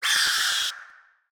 PixelPerfectionCE/assets/minecraft/sounds/mob/guardian/land_hit1.ogg at mc116
land_hit1.ogg